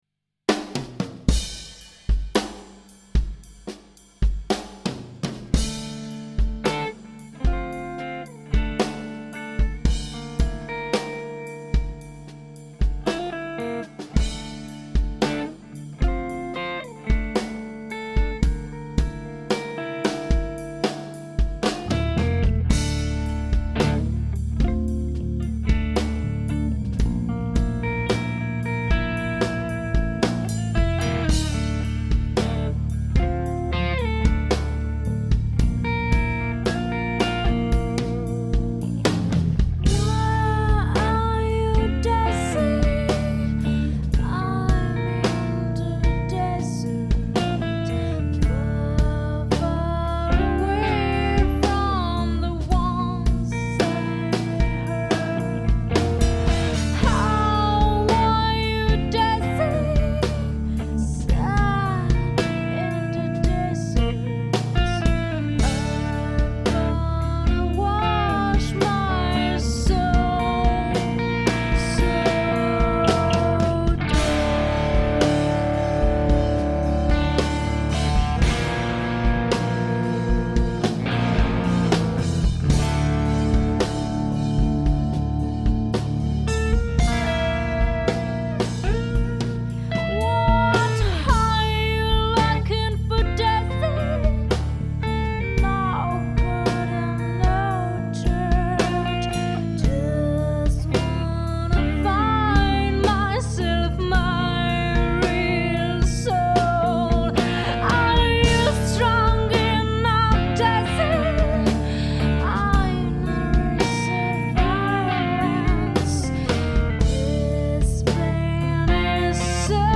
OK merci, c'est un outil super pratique ! ... ça m'a permis de recaler les phases de ma gratte pour récupérer l'épaisseur